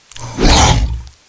hurt_1.ogg